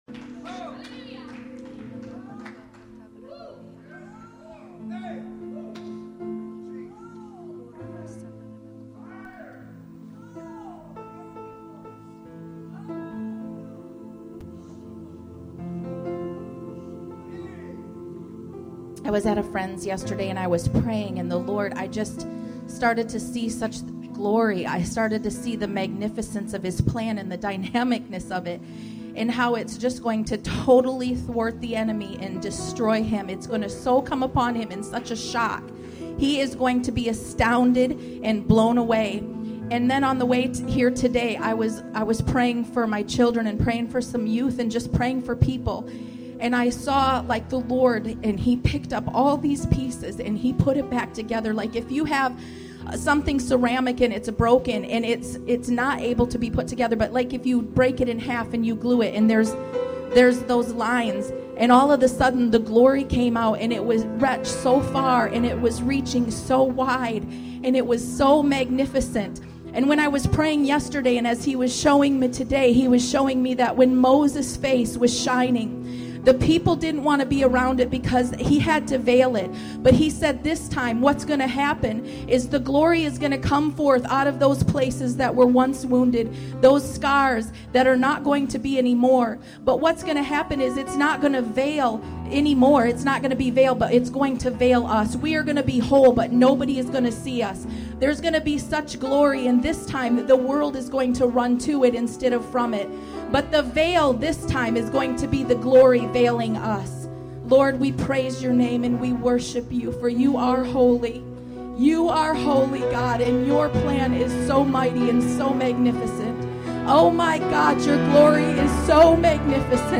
A must hear, 7 minute prophetic word.